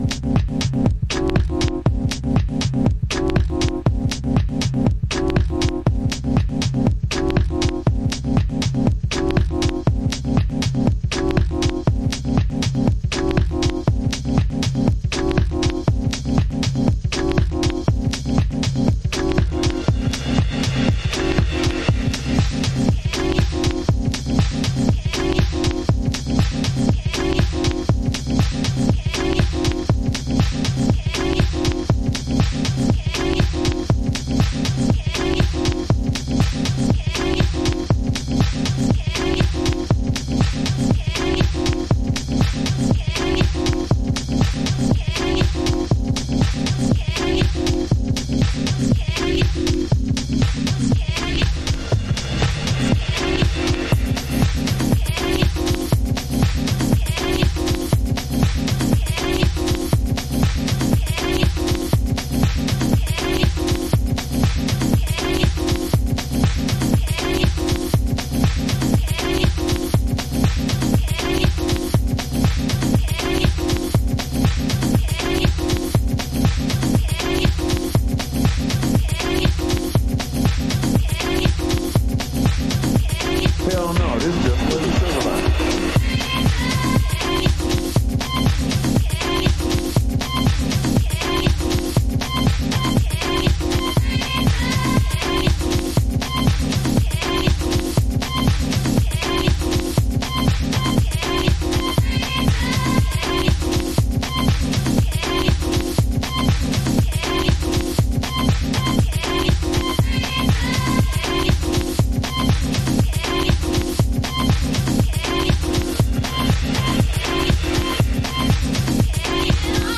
DETROIT-INFLUENCED HOUSE